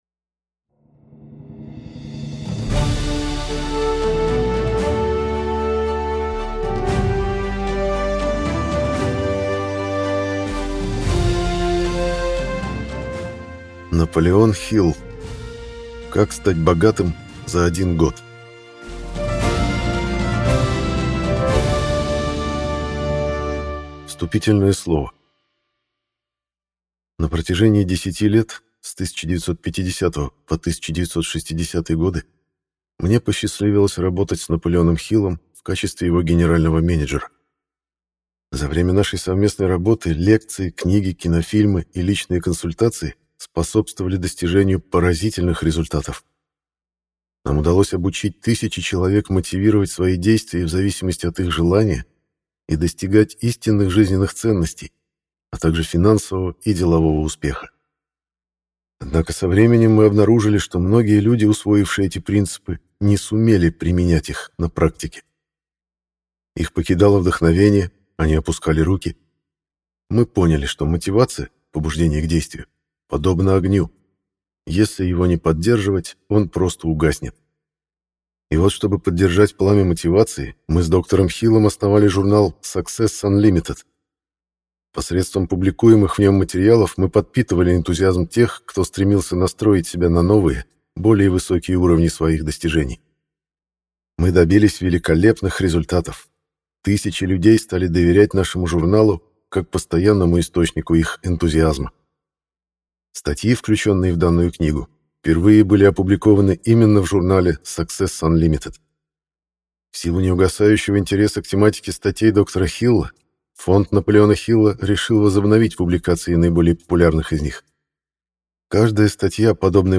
Аудиокнига Как стать богатым за один год | Библиотека аудиокниг